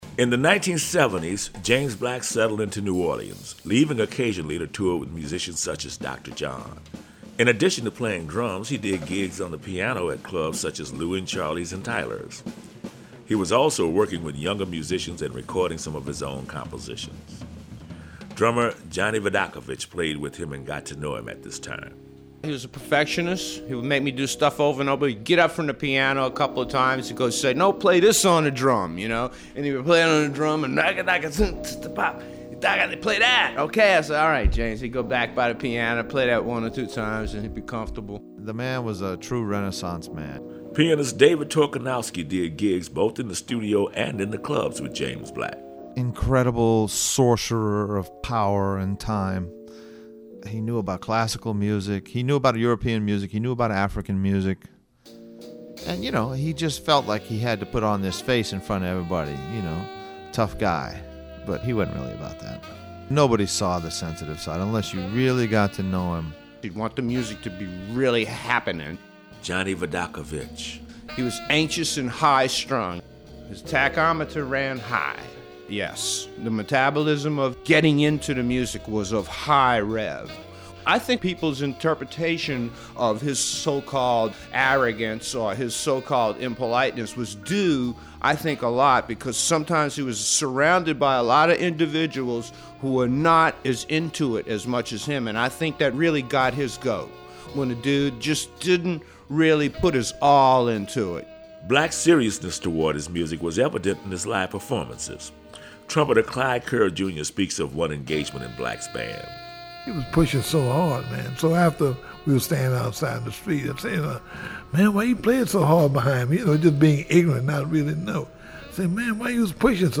Members of the jazz community in New Orleans